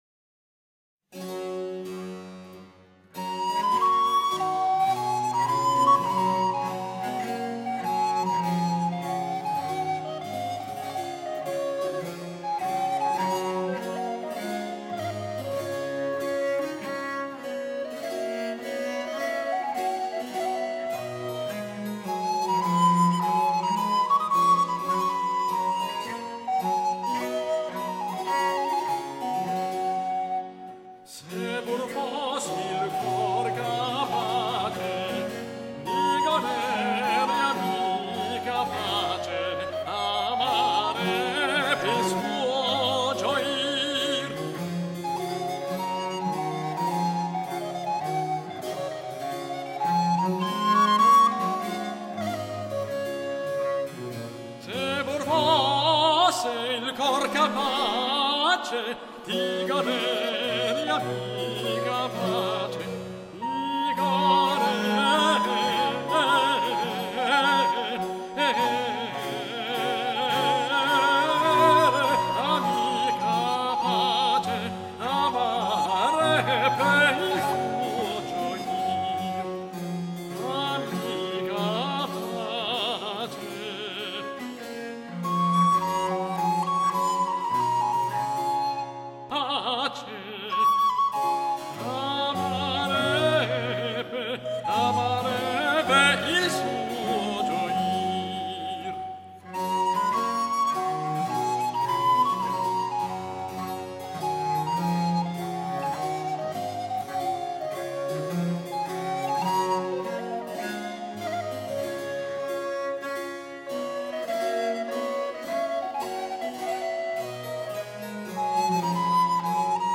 für Tenor, Blockflöte und Generalbass
-Aria